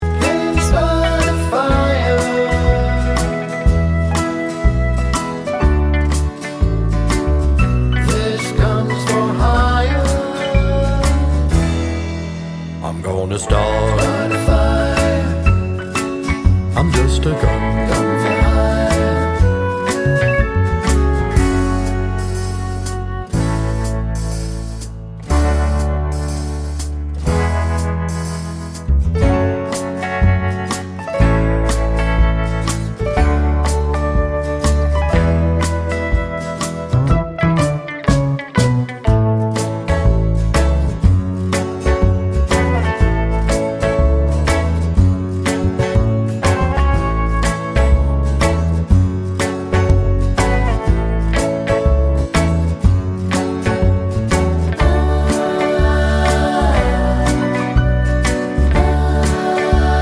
no Backing Vocals Pop (1980s) 3:19 Buy £1.50